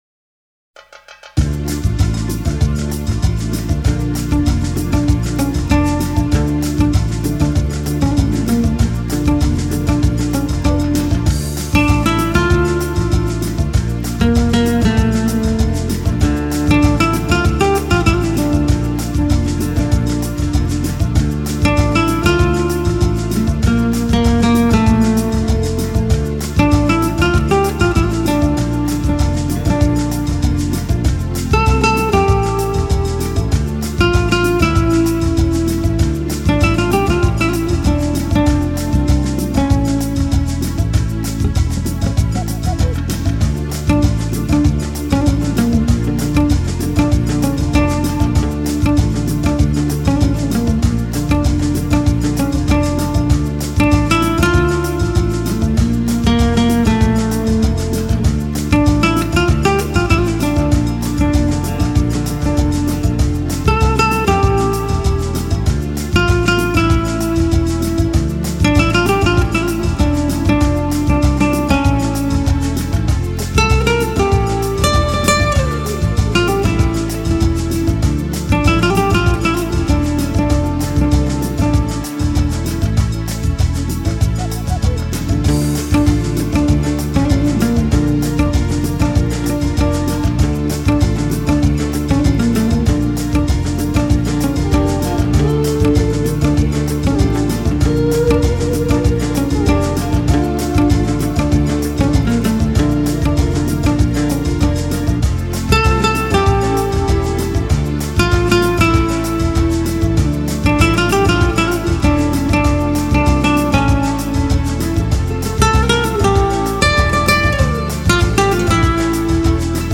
New Age or Smooth Jazz